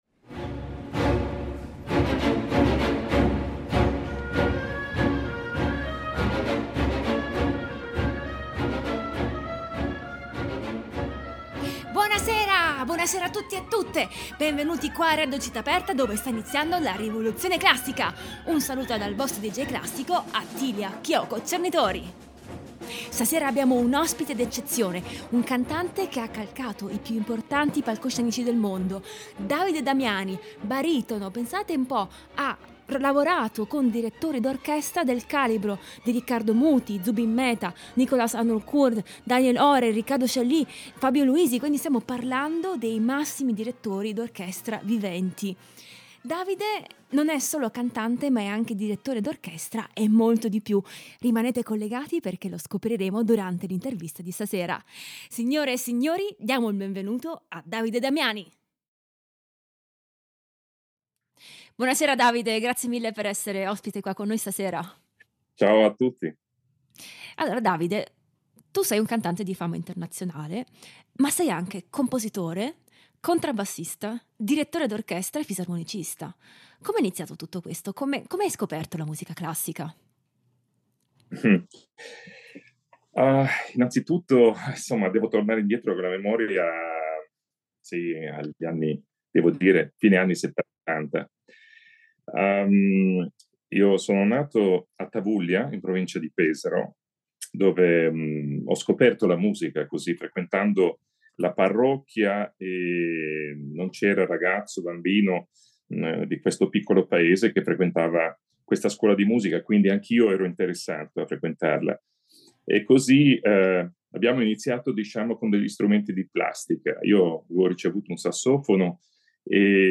Ospite di questa puntata il baritono